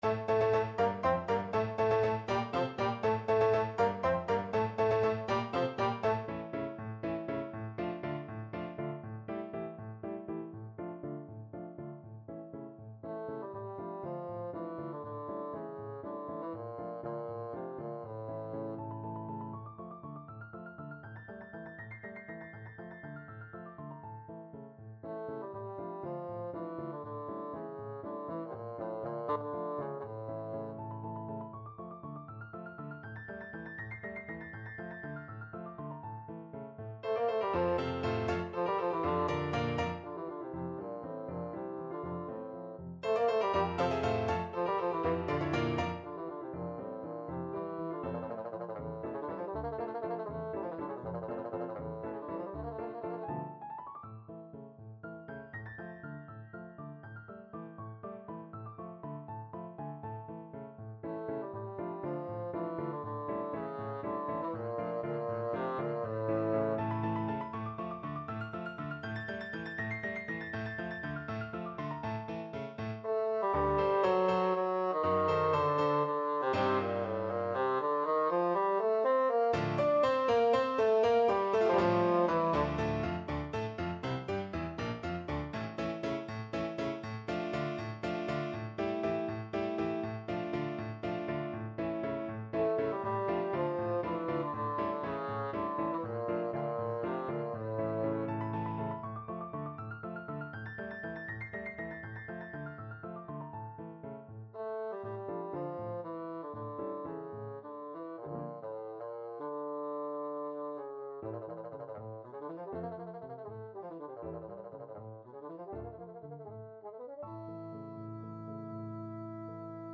Bassoon
D minor (Sounding Pitch) (View more D minor Music for Bassoon )
3/8 (View more 3/8 Music)
Allegro vivo (.=80) (View more music marked Allegro)
Classical (View more Classical Bassoon Music)